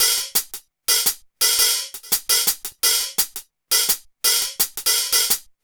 Hi Hat 08.wav